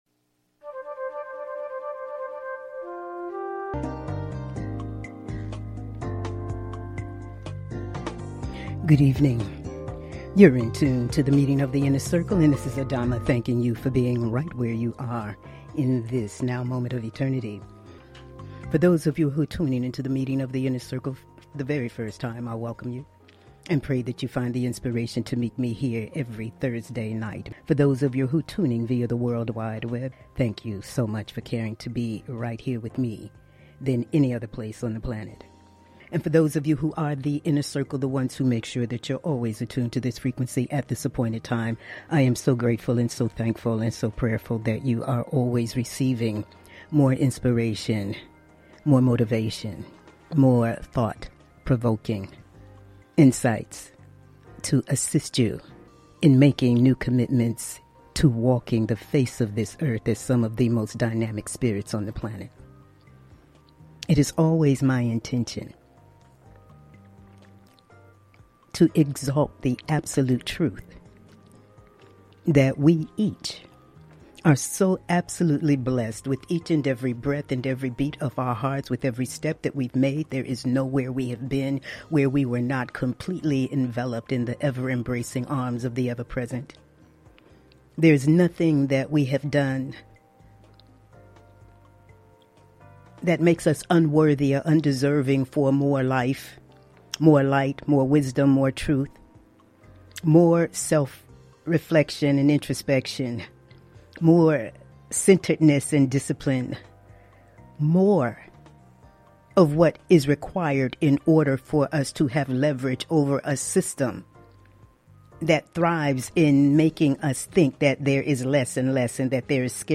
Talk Show Episode
Monologues